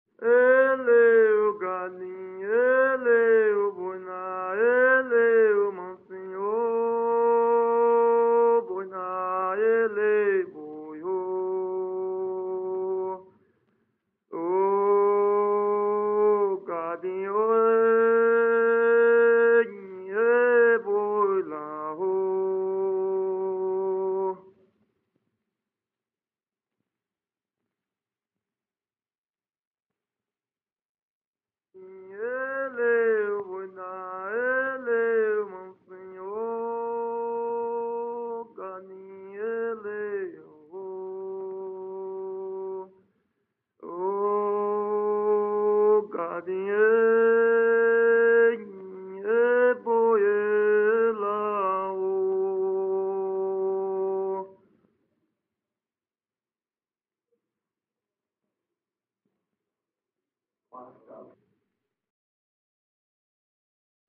Aboio - ""O guia de aboiado""